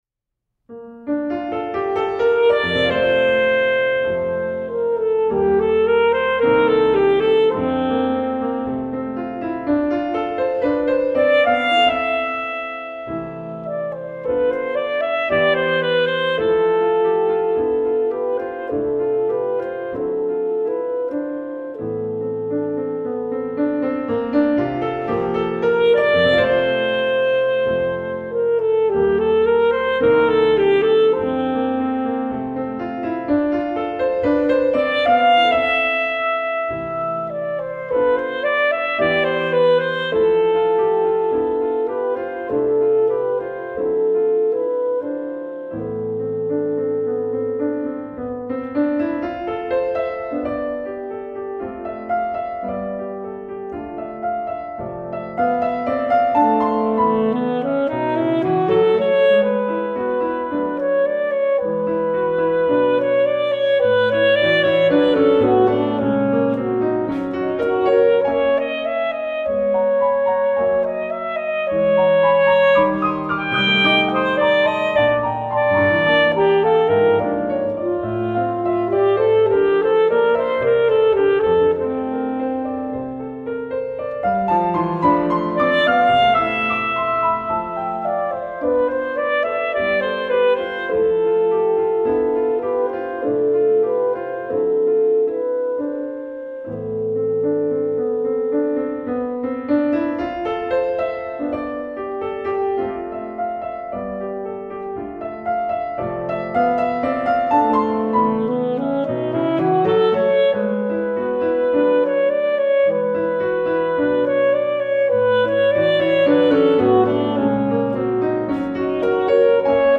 1. Moderately